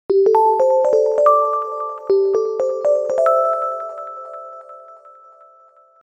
Звуки звонящего телефона
Звук эха при звонке на сотовый смартфон